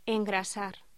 Locución: Engrasar